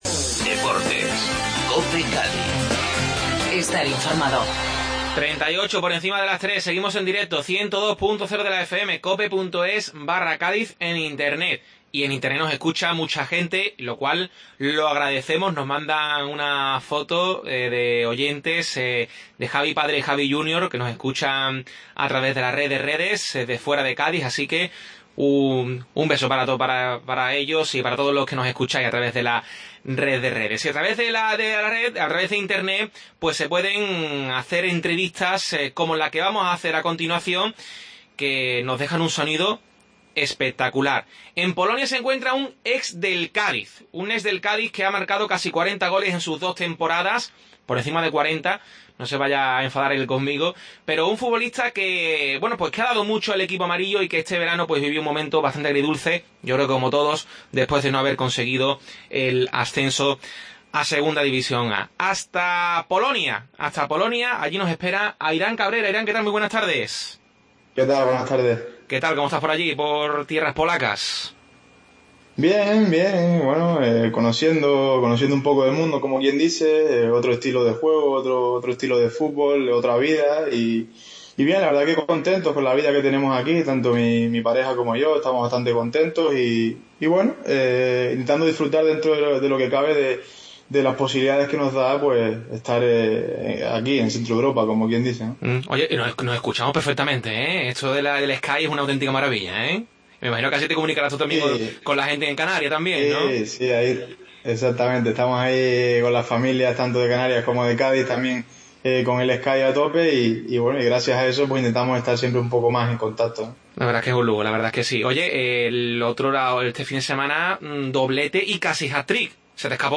Redacción digital Madrid - Publicado el 17 dic 2015, 20:25 - Actualizado 18 mar 2023, 02:52 1 min lectura Descargar Facebook Twitter Whatsapp Telegram Enviar por email Copiar enlace El ex del Cádiz Airam Cabrera nos atiende desde Polonia por primera vez desde que dejó de ser jugador amarillo. Acabamos con el capítulo 70 de Fábulas Cadistas